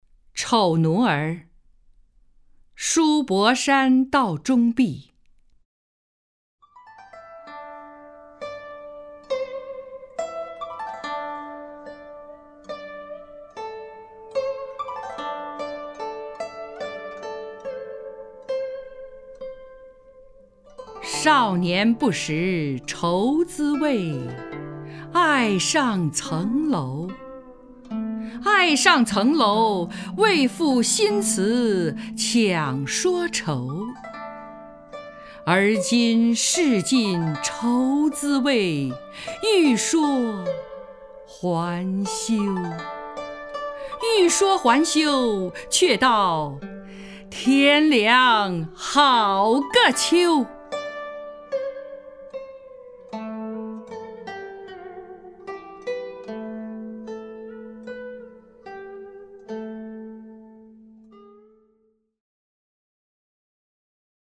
张筠英朗诵：《丑奴儿·书博山道中壁》(（南宋）辛弃疾)
名家朗诵欣赏 张筠英 目录